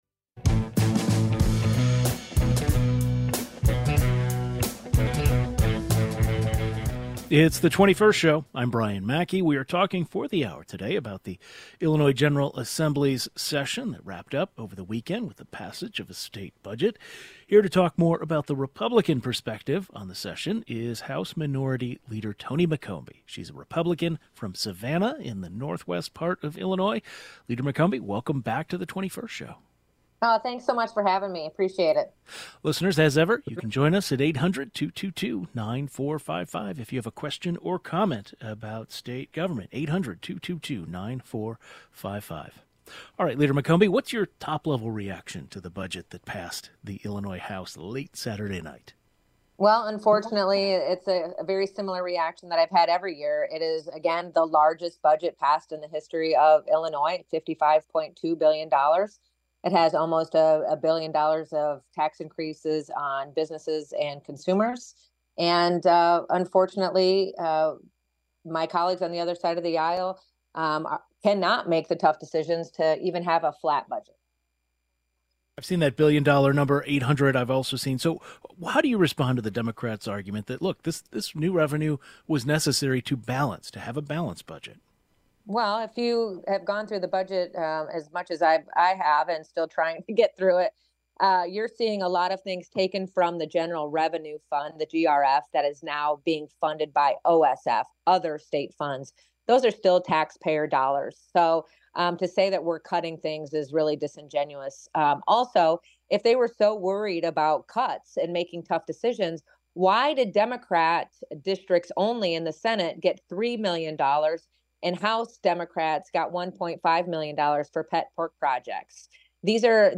The Illinois General Assembly session wrapped up over the weekend with the passage of a state budget. House Minority Leader, Tony McCombie shares the Republican perspective on the session.